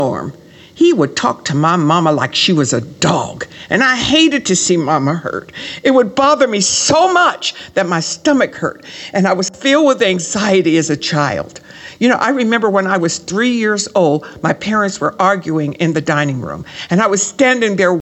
Post-Mastering Sample